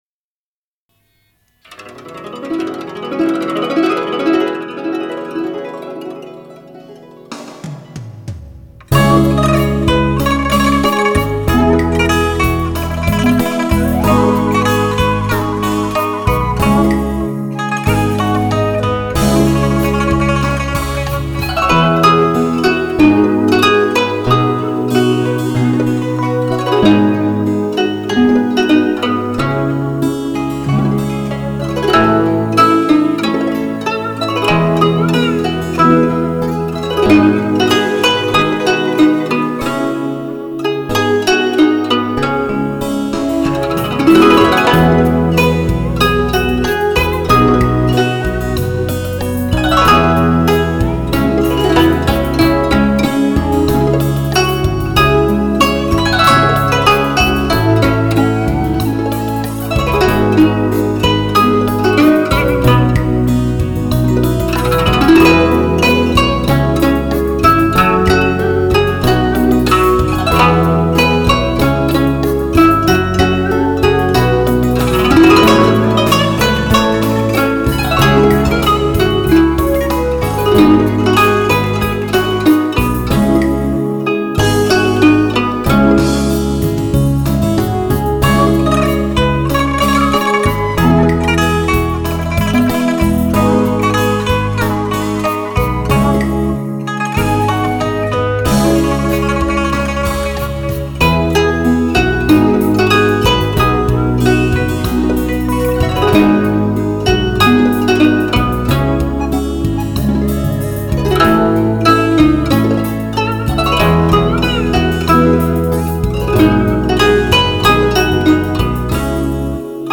3周前 纯音乐 4